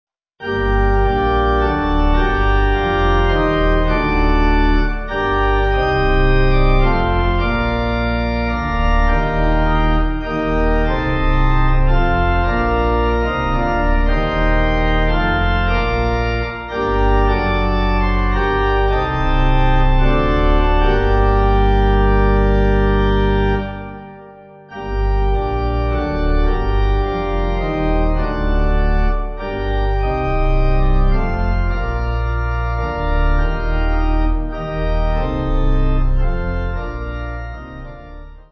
Organ
(CM)   6/Gm